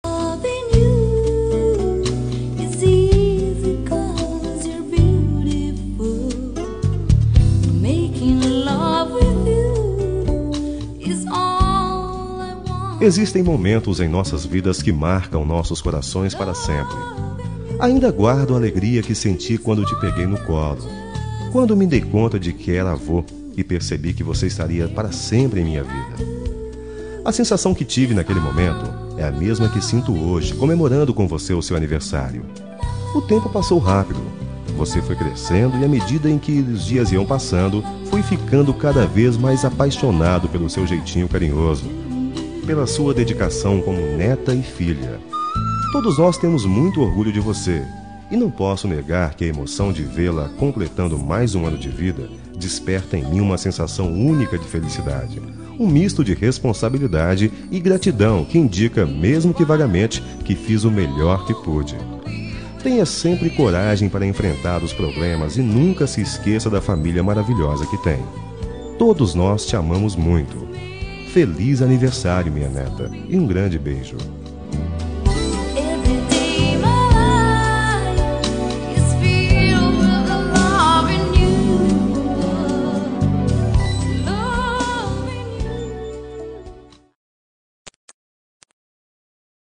Aniversário de Neta – Voz Masculina – Cód: 131034